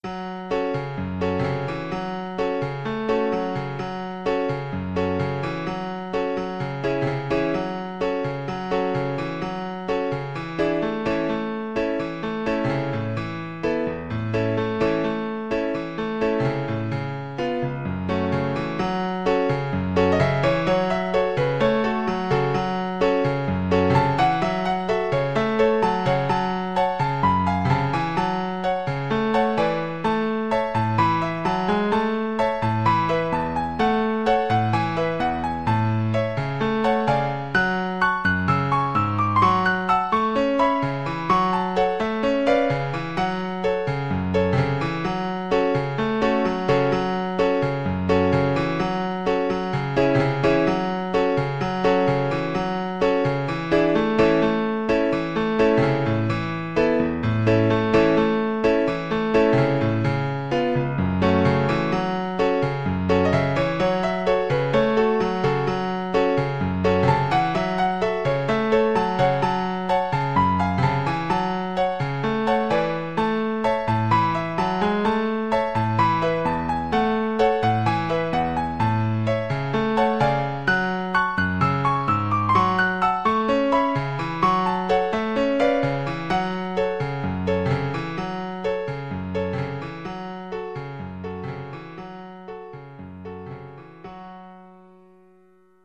Allegro e cantabile = c. 128 (View more music marked Allegro)
4/4 (View more 4/4 Music)
F# minor (Sounding Pitch) (View more F# minor Music for Violin )
Violin  (View more Intermediate Violin Music)